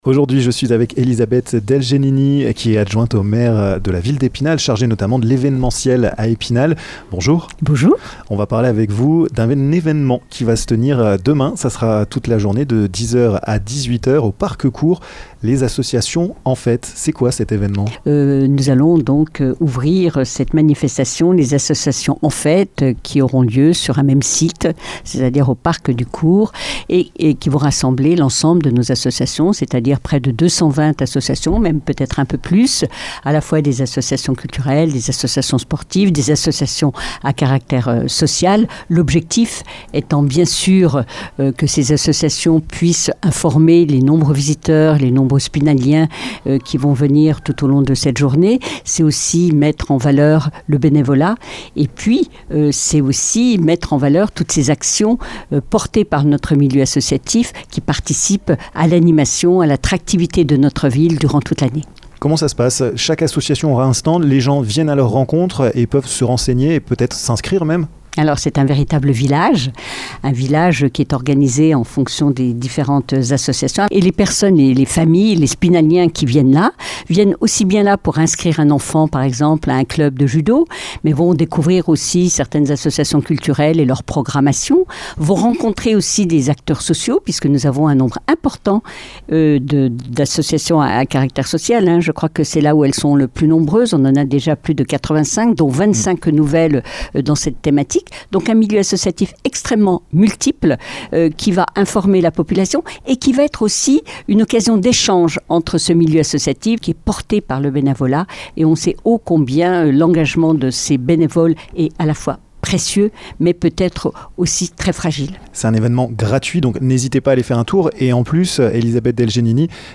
Elisabeth Del Genini, adjointe au maire en charge notamment de l'événementiel, a accepté de répondre à nos questions.